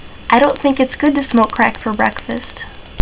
crackbreak.wav